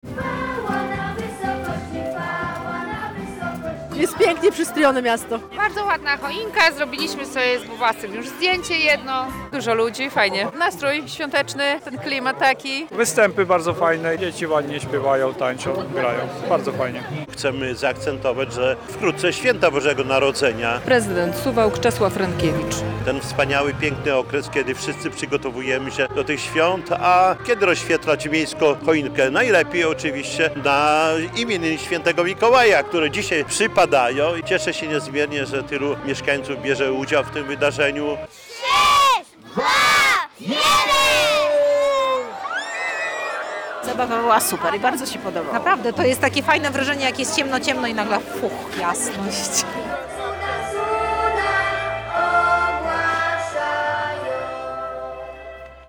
W iście baśniowej atmosferze suwalczanie rozświetlili w sobotę (06.12) miejską choinkę. Tradycyjnie wydarzeniu towarzyszyły występy artystyczne.